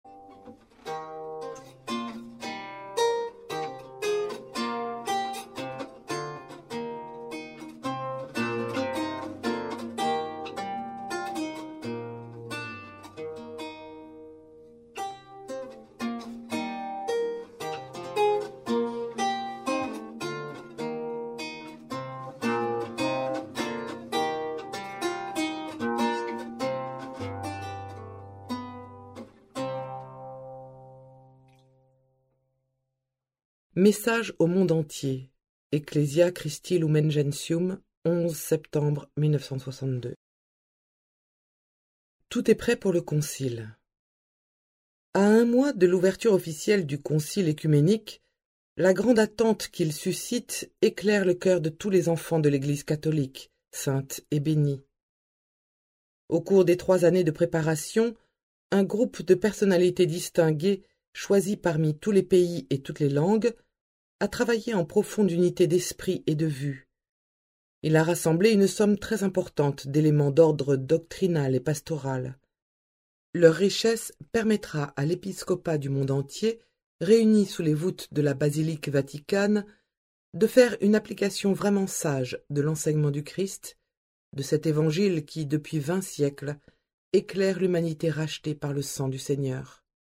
En huit conférences thématiques, Monseigneur Gérard Defois,évêque émérite de Lille, présente d’une manière claire et documentée les principaux textes du grand Concile Vatican II. Une manière simple et passionnante de (re)découvrir les grands documents conciliaires.